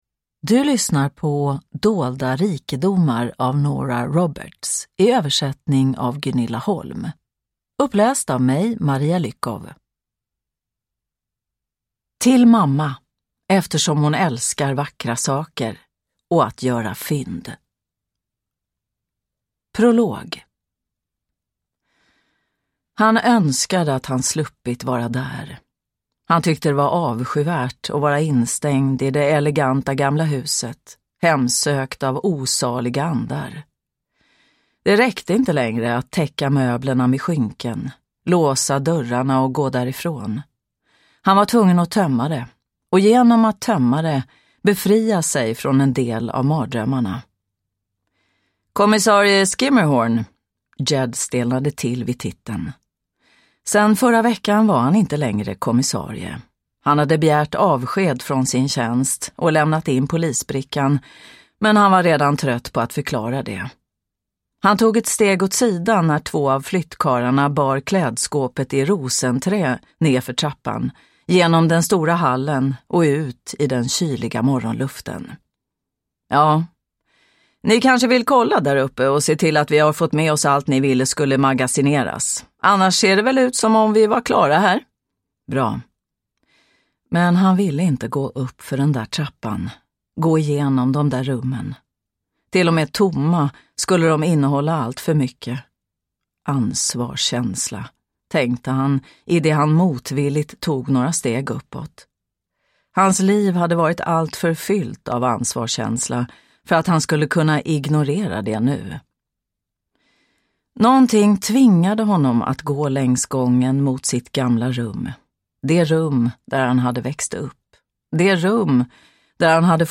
Dolda rikedomar – Ljudbok – Laddas ner